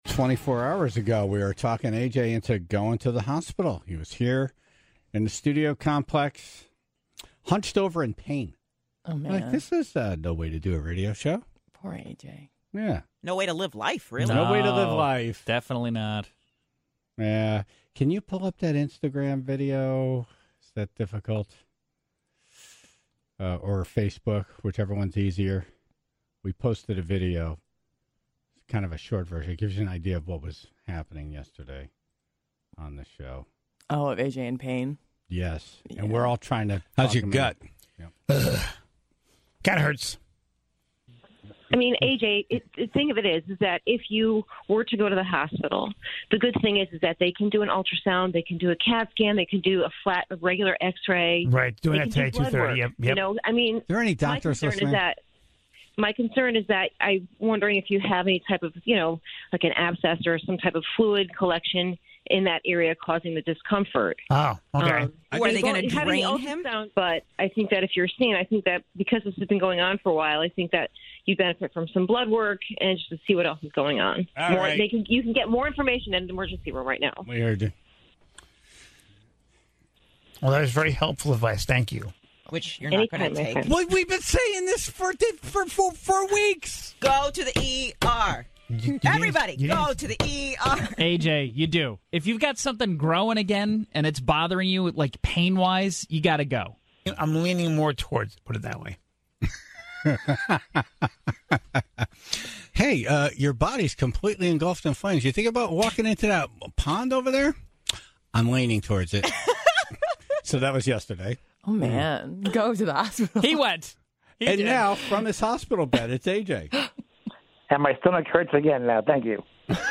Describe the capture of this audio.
Today, he was on the phone, live from his hospital bed with an update on his recovery and to complain about the lack of food he's had.